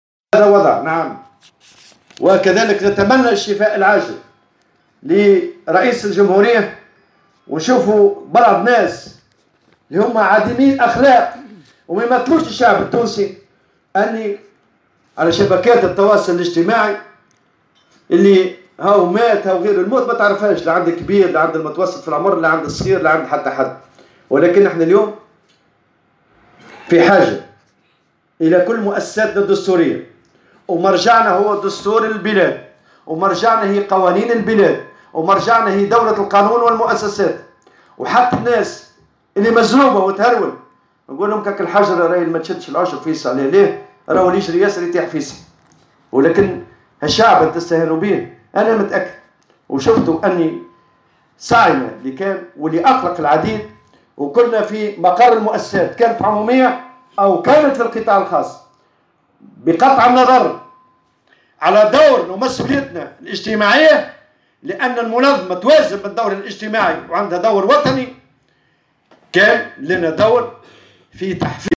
عبر الأمين العام للإتحاد العام التونسي للشغل نور الدين الطبوبي في تصريح لمراسلة الجوهرة "اف ام" اليوم الجمعة في نابل عن تمنياته بالشفاء لرئيس الجمهورية واصفا مروجي إشاعة وفاته بعديمي الأخلاق والذين لا يريدون الخير لتونس .